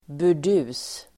Uttal: [bur_d'u:s]